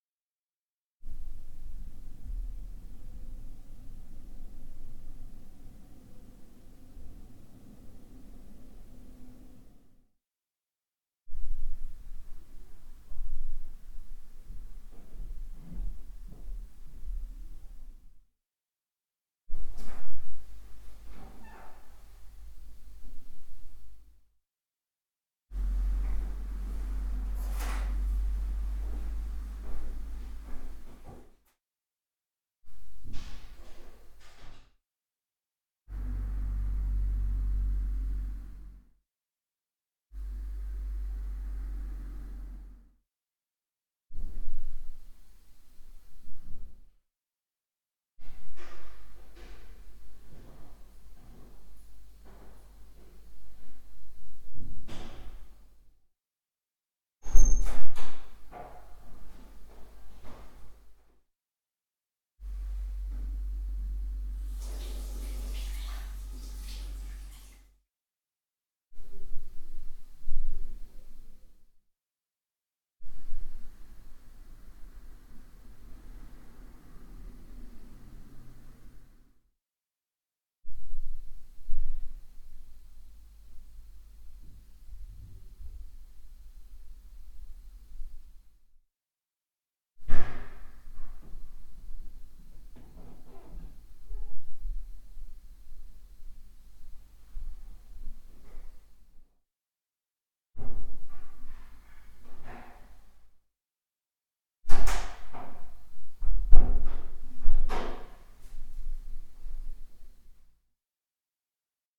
electroacoustic music
1-Kanal-Audio
All sounds below a threshold of 0.9 percent were removed.